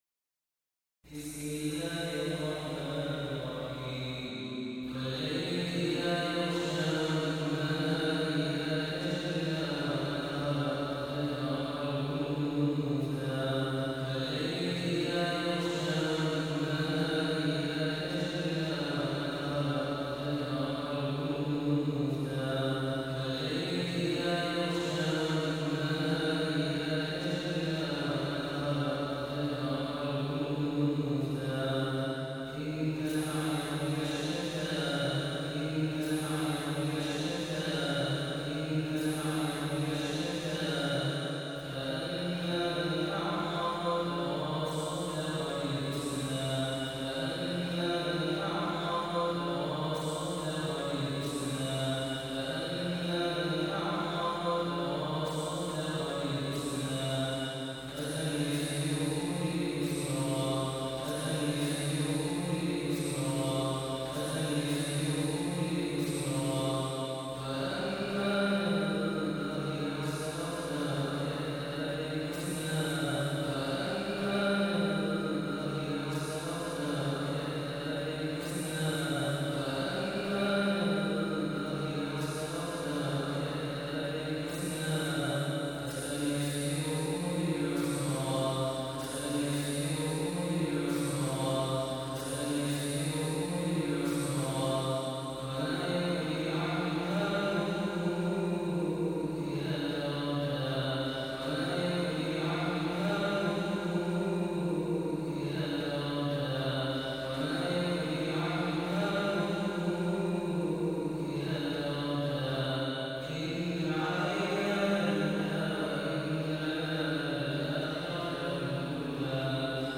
المصحف المترجم - التركية